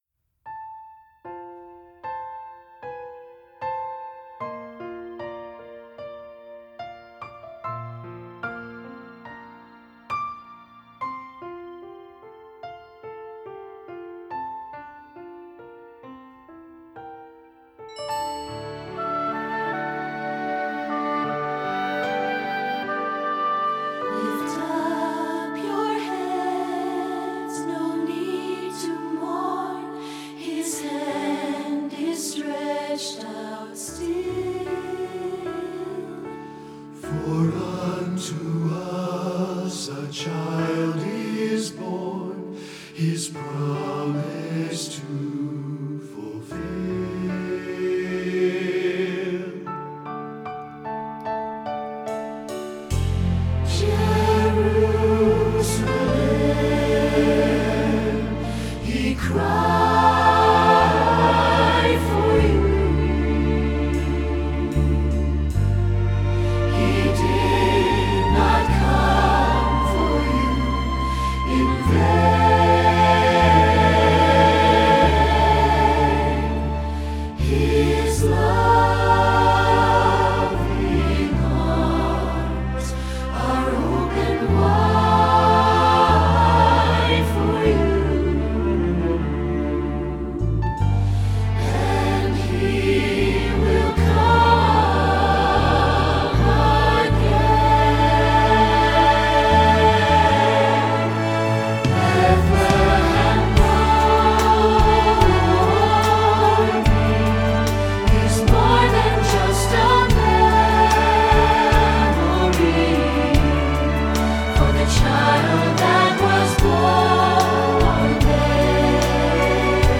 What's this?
Voicing: SATB,Pno